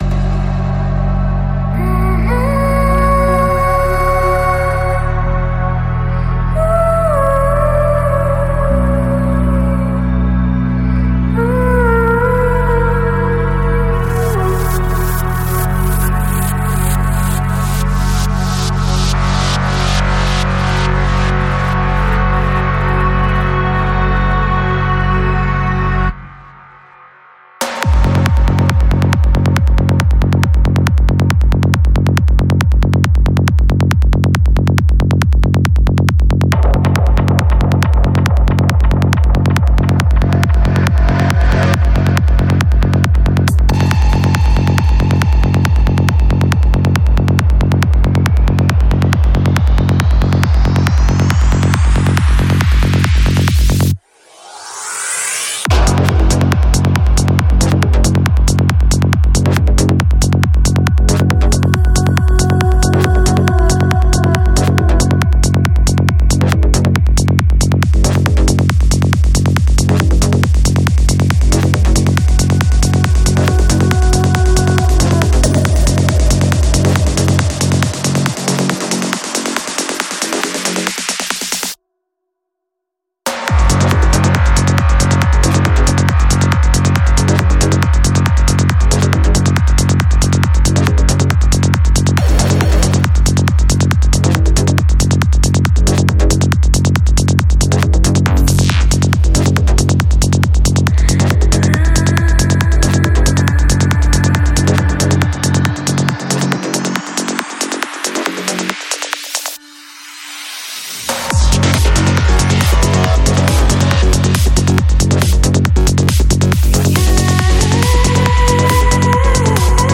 Жанр: Psychedelic Trance